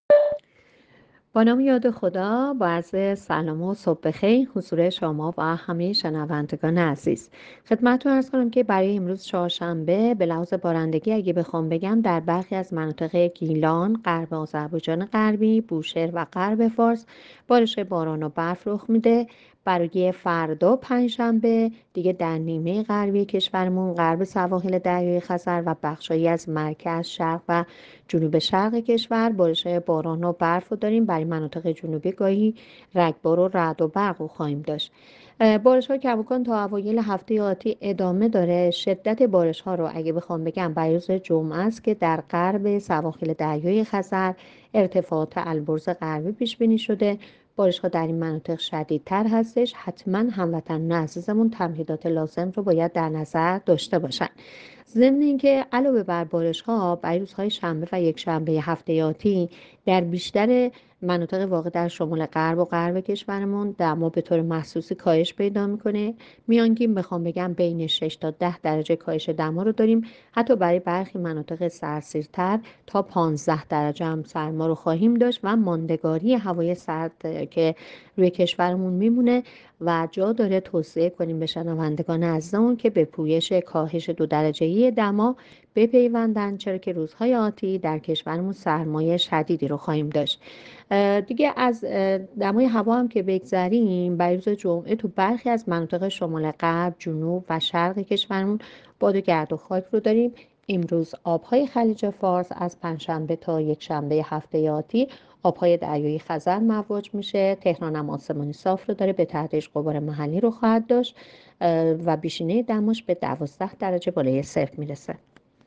گزارش رادیو اینترنتی پایگاه‌ خبری از آخرین وضعیت آب‌وهوای یکم اسفند؛